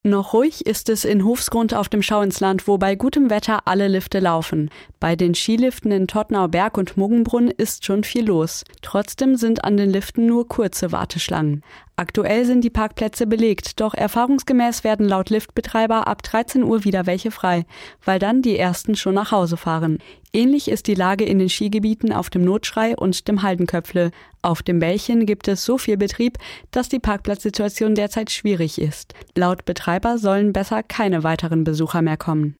SWR-Reporterin